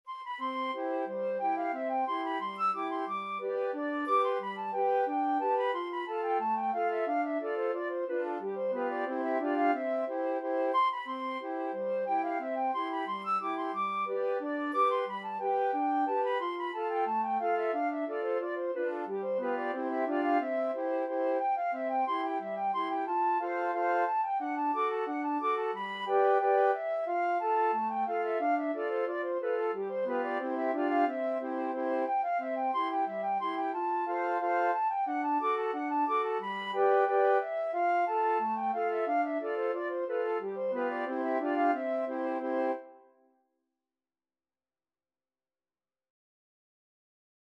Flute 1Flute 2Flute 3Alto Flute
C major (Sounding Pitch) (View more C major Music for Flute Quartet )
Molto Allegro = 180 (View more music marked Allegro)
2/2 (View more 2/2 Music)
Flute Quartet  (View more Intermediate Flute Quartet Music)
sailors_hornpipe_4FL.mp3